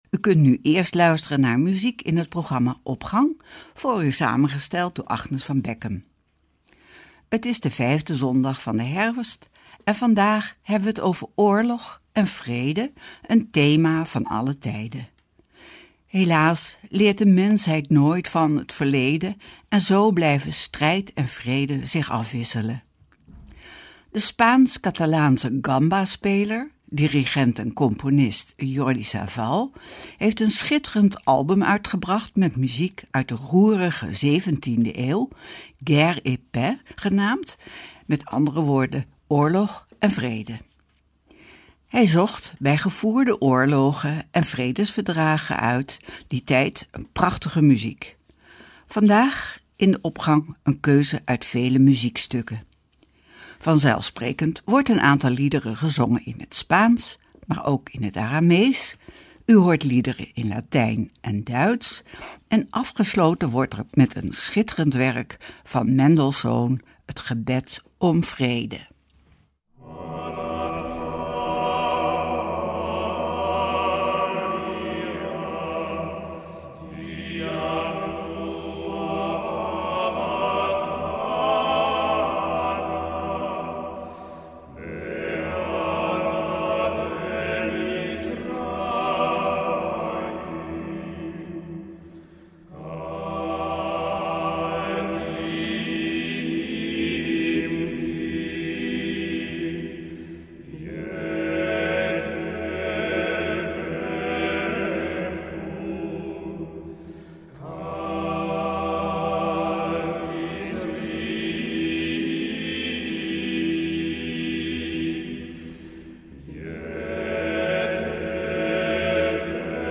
muziek uit de roerige zeventiende eeuw